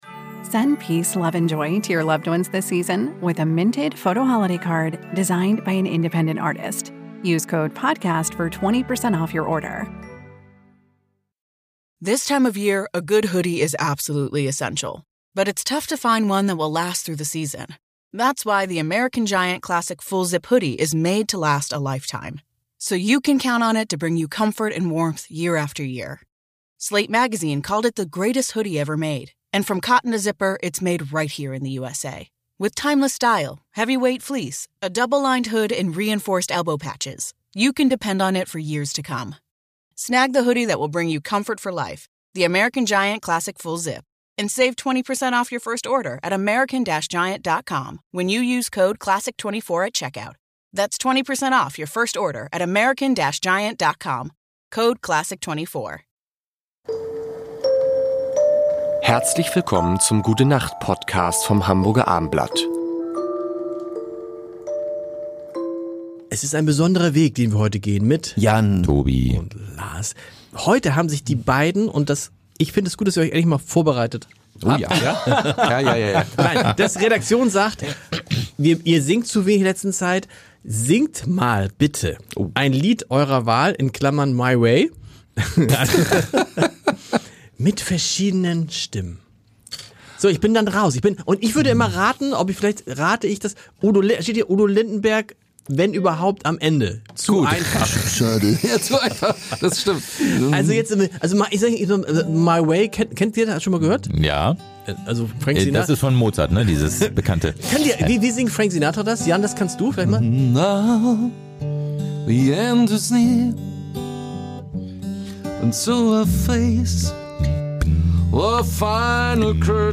Prominente singen eines der größten Lieder aller Zeiten